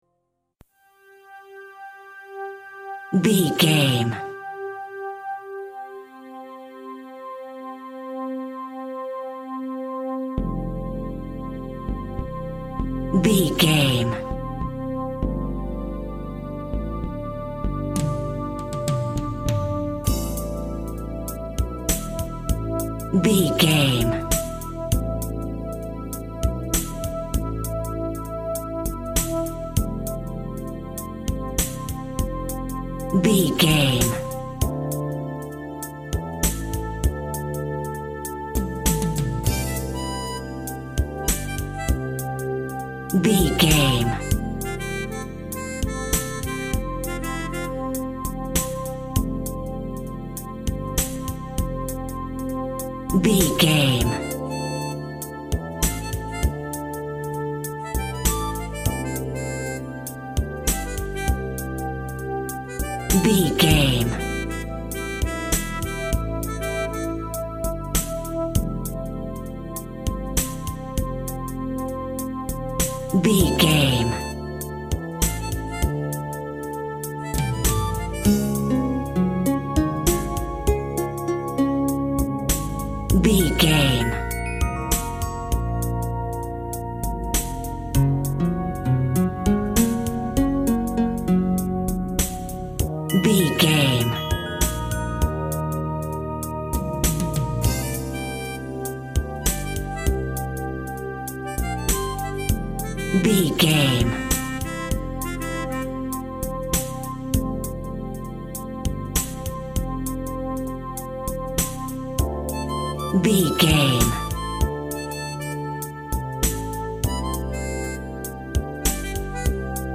Retro Eighties.
Ionian/Major
Slow
funky
groovy
peaceful
bass guitar
synthesiser
drums
acoustic guitar
harp
electric piano
synth bass
synth lead